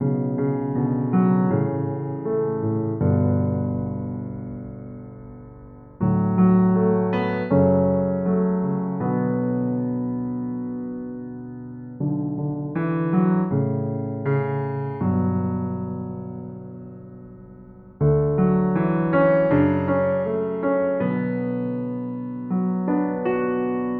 Dark Keys 1 BPM 80.wav